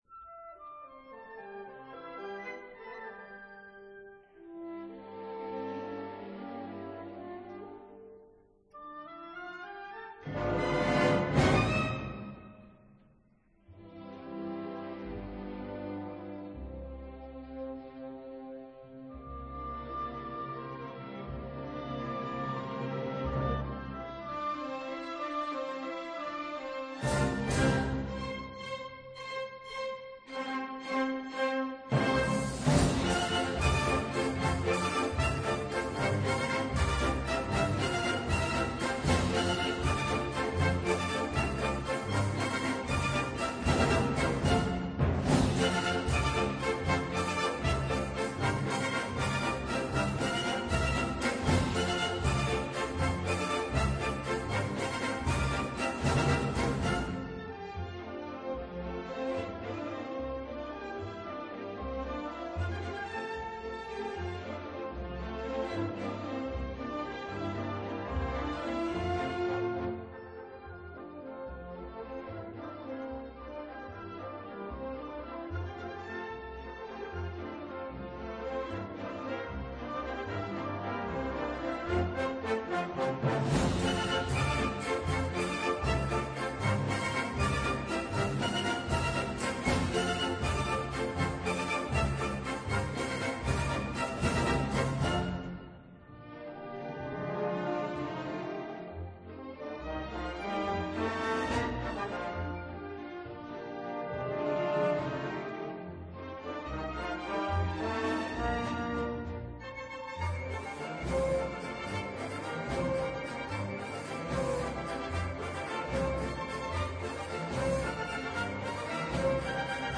La sintonía de Agropopular en el Concierto de Año Nuevo en Viena